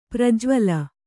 ♪ prajvala